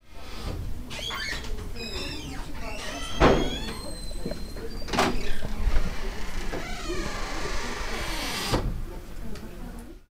holl_skipuchaya_dver.mp3